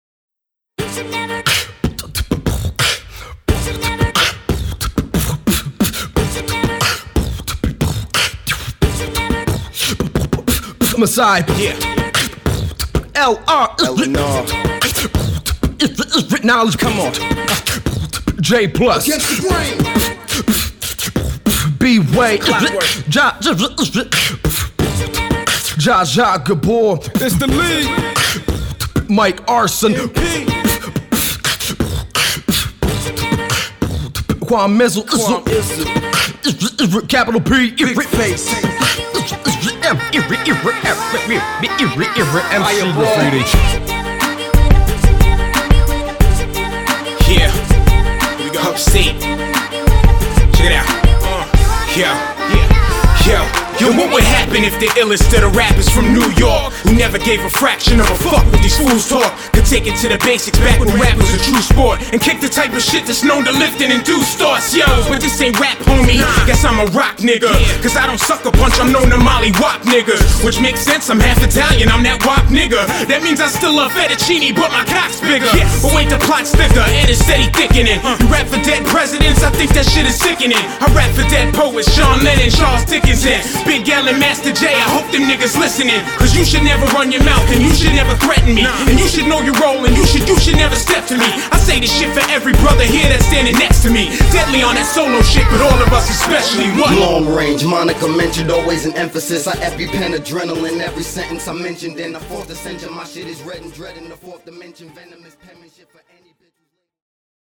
HIP_HOP_MIX_2.mp3